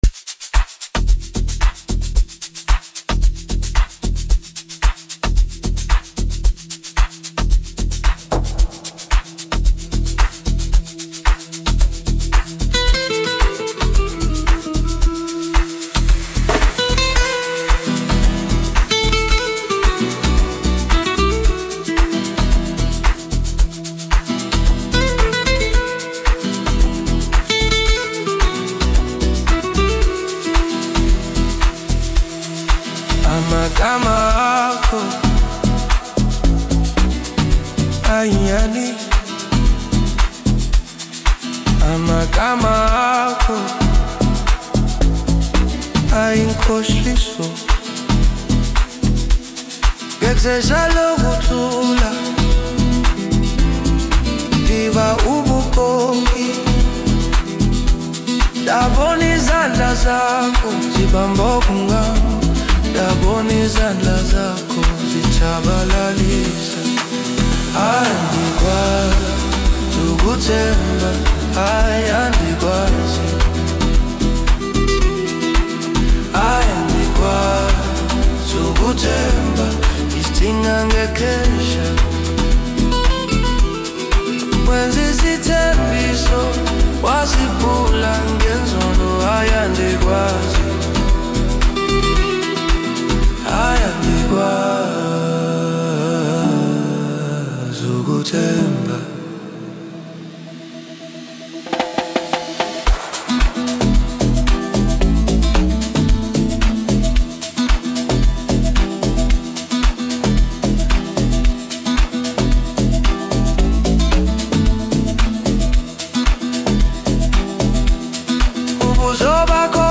Home » Amapiano
South African Music Producer and singer